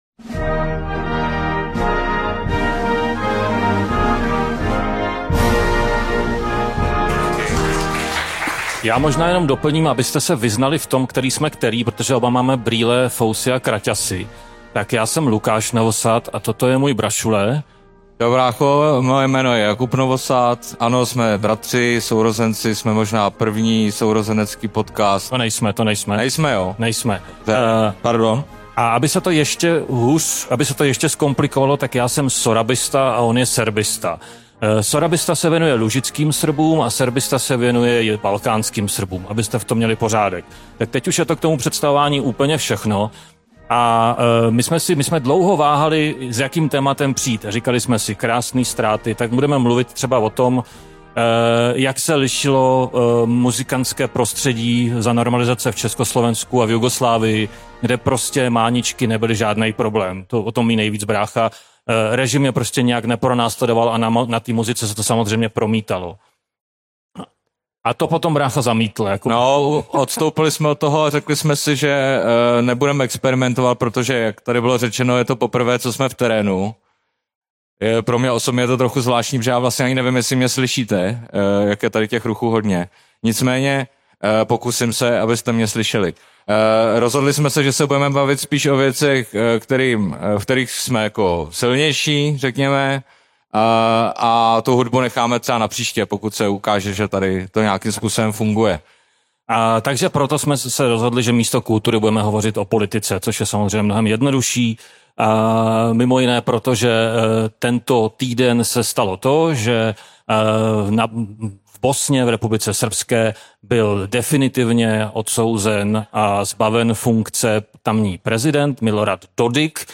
První veřejné vystoupení sourozeneckého podcastu Hej, Slované! se odehrálo v srpnu 2025 na festivalu Krásný ztráty ve Všeticích.